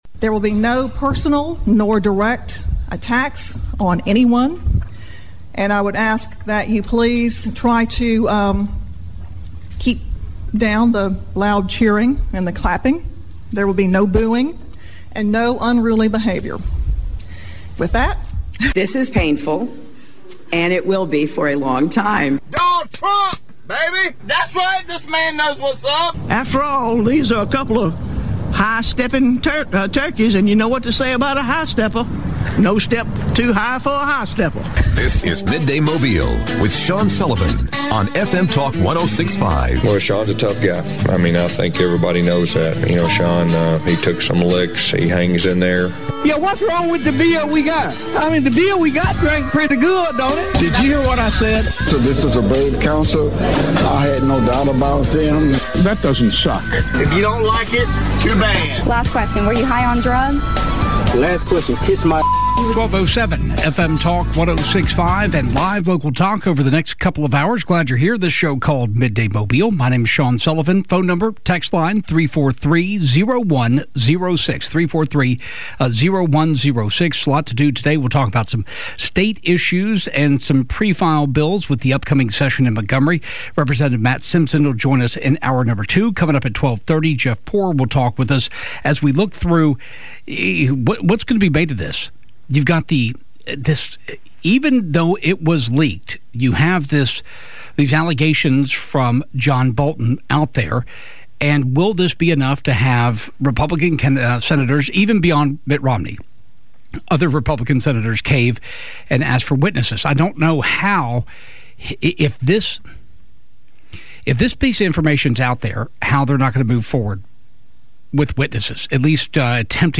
President Trump's defense team speaks at his impeachment trial.